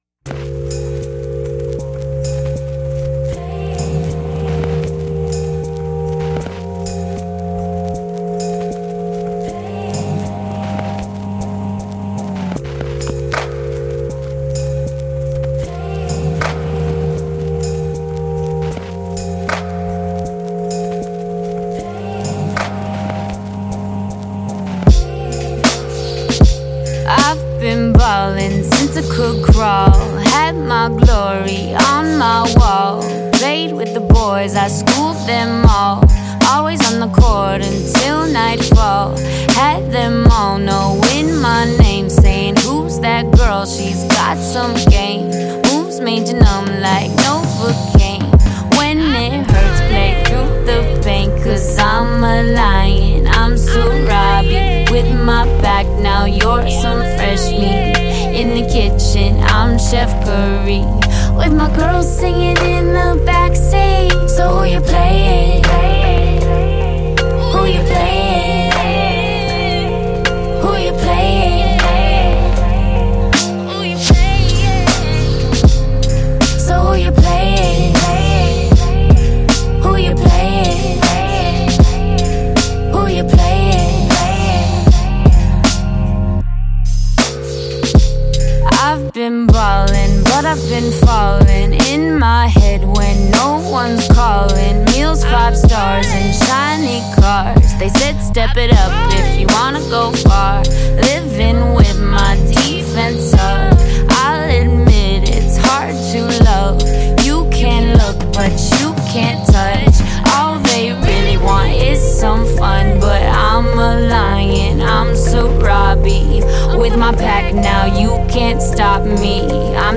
# R&B & Soul